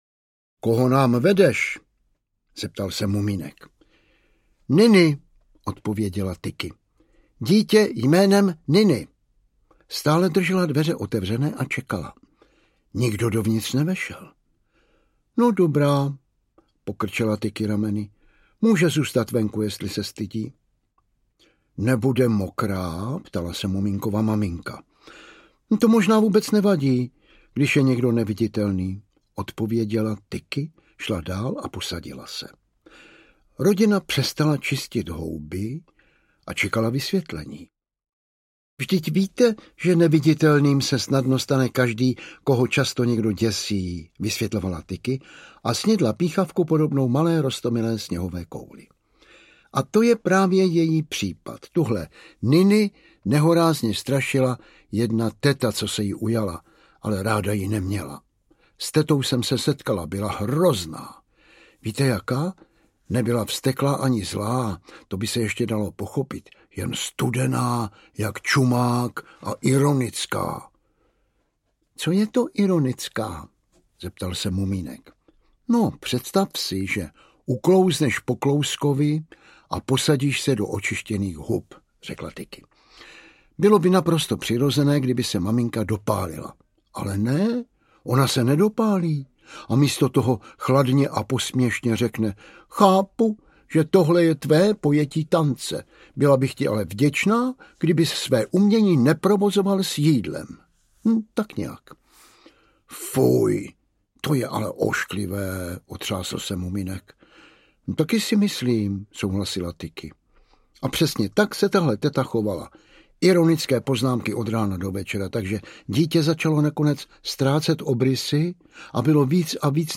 Neviditelné dítě a jiné příběhy audiokniha
Ukázka z knihy
Vyrobilo studio Soundguru.